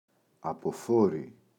αποφόρι, το [apo’fori]